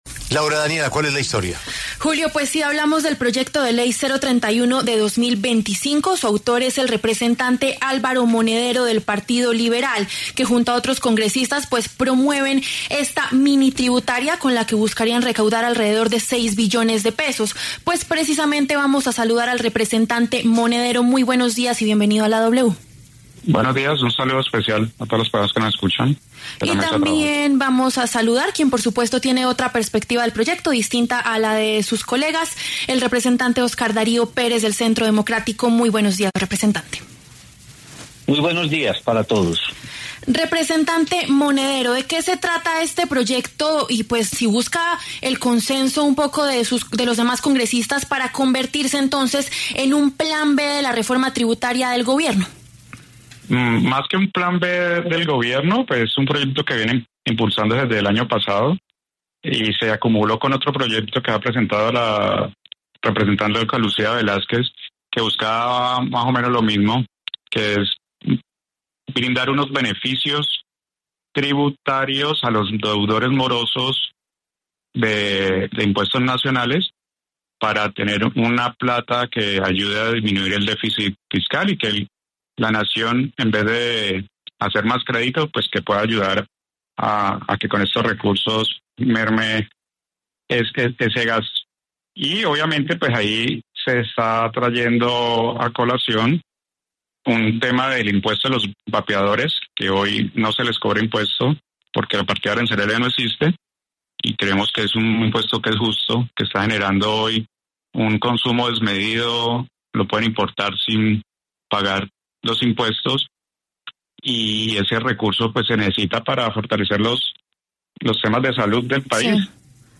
La iniciativa es la 031 de 2025, y su autor es el representante liberal Álvaro Monedero, quien pasó por los micrófonos de La W.